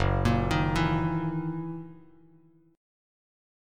F#13 chord